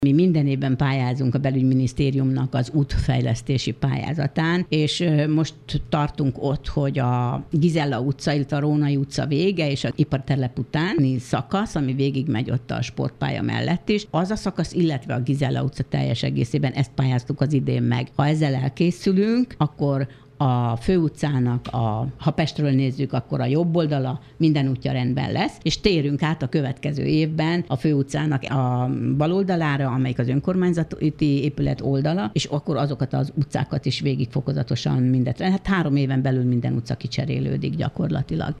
Kakucson a Gizella utcát újítják fel még ebben az évben a pénzből. A rekonstrukció után a Fő út egyik oldalán minden utca elkészül, jövőre pedig elkezdik a másik oldal útjainak felújítását - mondta dr. Kendéné Toma Mária polgármester.